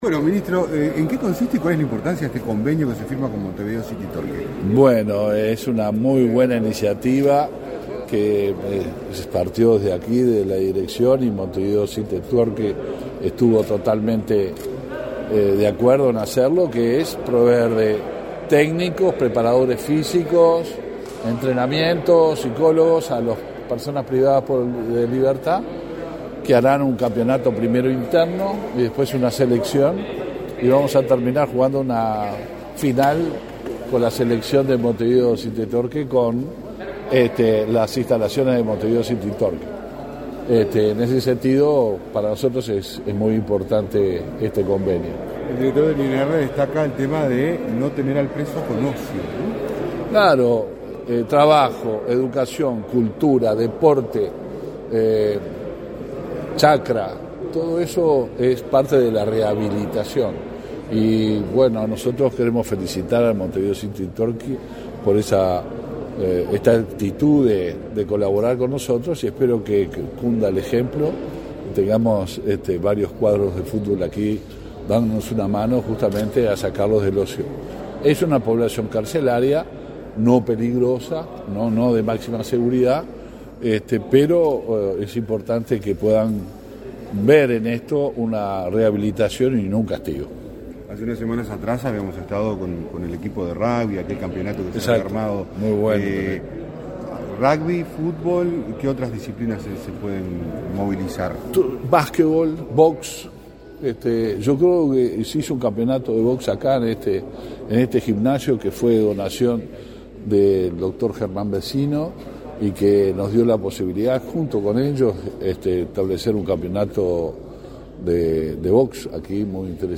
Declaraciones a la prensa del ministro del Interior, Luis Alberto Heber
Declaraciones a la prensa del ministro del Interior, Luis Alberto Heber 17/02/2022 Compartir Facebook X Copiar enlace WhatsApp LinkedIn Tras participar en la firma de convenio entre el Ministerio del Interior y el club Montevideo City Torque, este 17 de febrero, el ministro Heber efectuó declaraciones a la prensa.